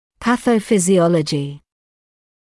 [ˌpæθəuˌfɪzɪ’ɔləʤɪ][ˌпэсоуˌфизи’олэджи]патофизиология